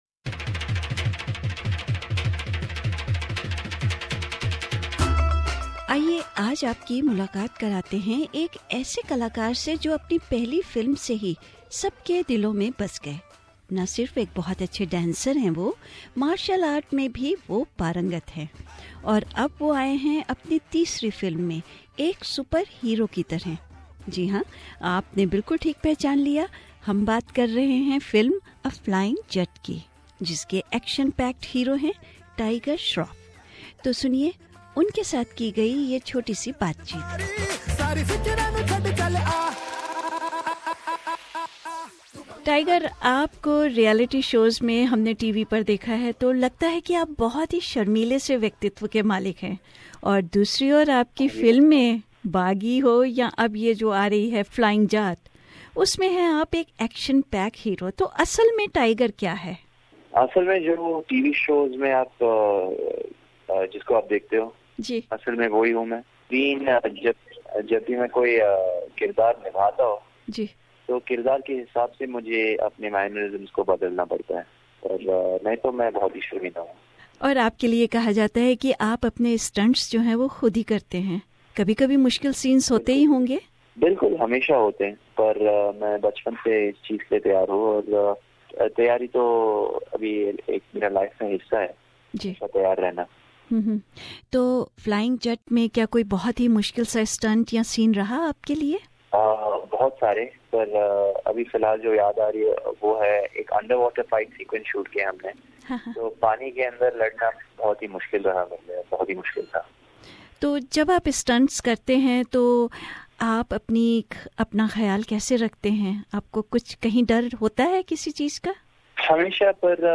टाइगर श्राफ की एक बातचीत...